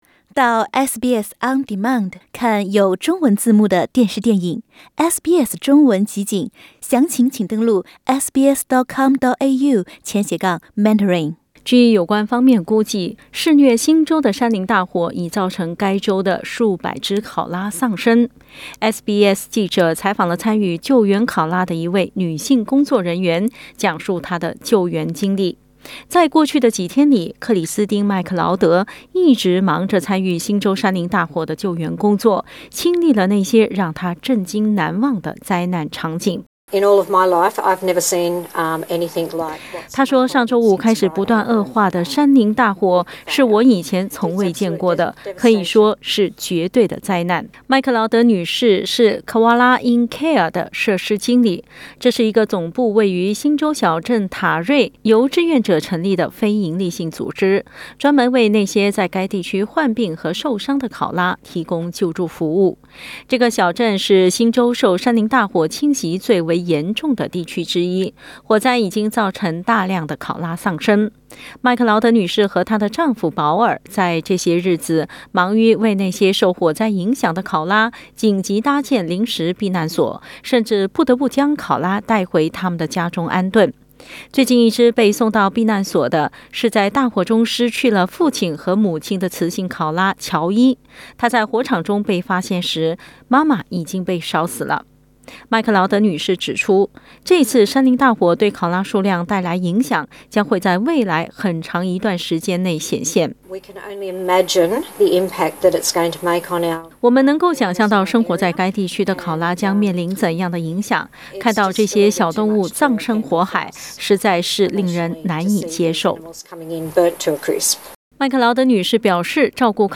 据有关方面估计，肆虐新南威尔士州的山林大火已造成该州的数百只考拉丧生。 SBS记者采访了参与救援考拉的一位女性工作人员，讲述她的救援经历。